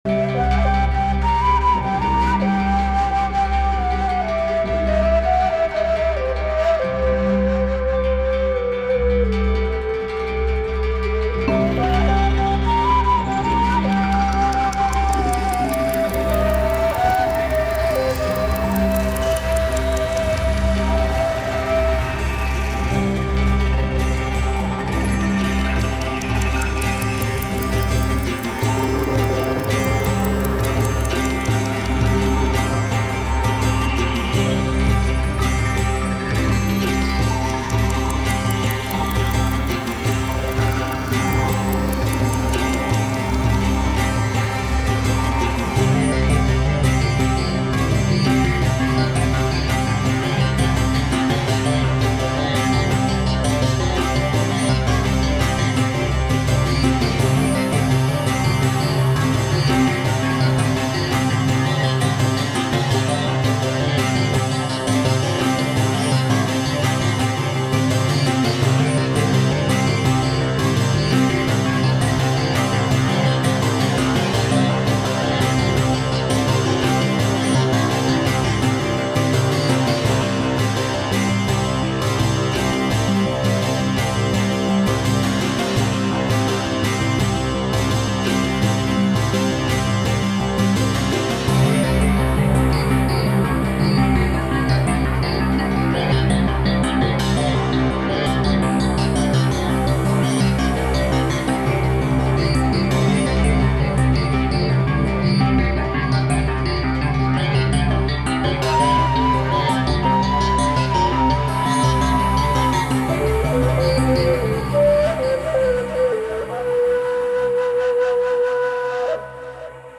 中東の民族楽器とシンセサイザーを組み合わせて作っており、寒気と緊張感が感じられる雰囲気を意識している。